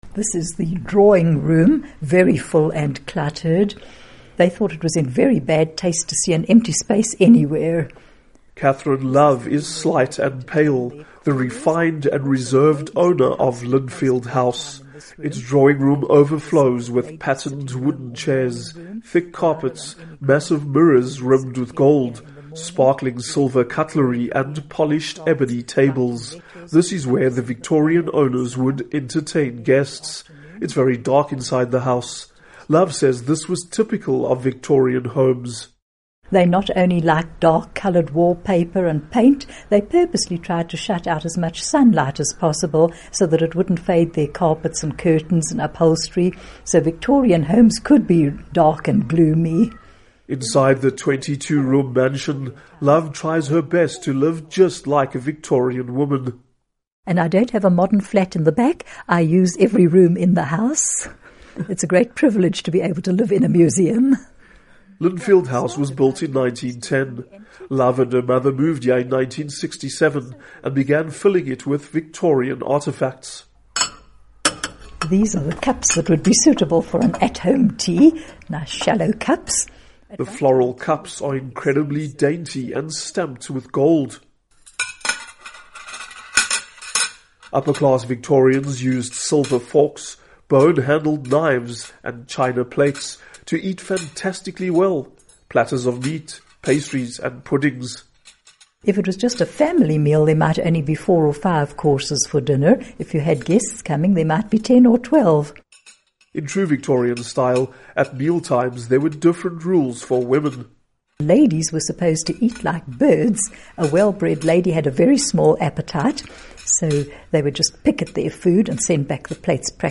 Audio tour of a 22-room mansion